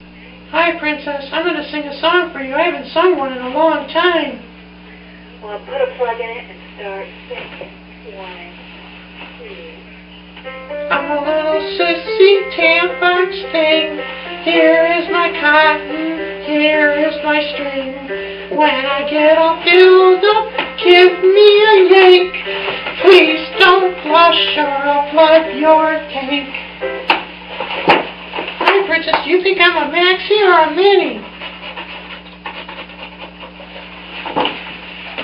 Singing tampon